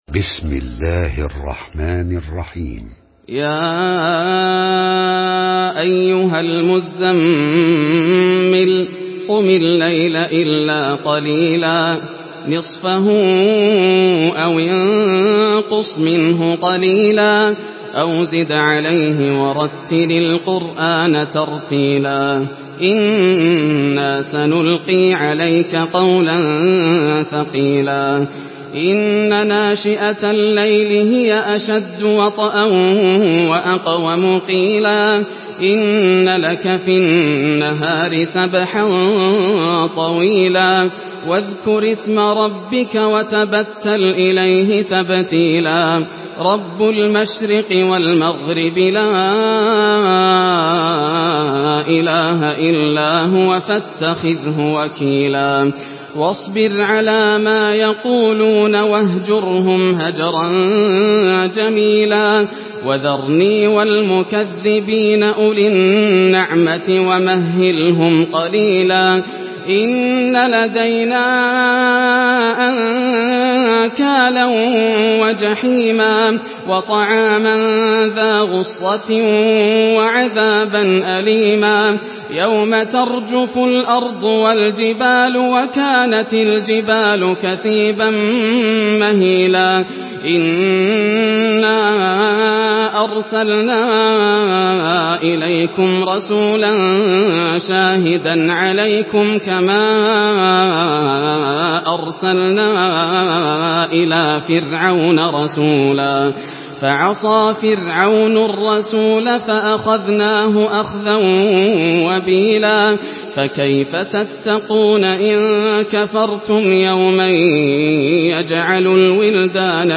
Surah المزمل MP3 by ياسر الدوسري in حفص عن عاصم narration.
مرتل حفص عن عاصم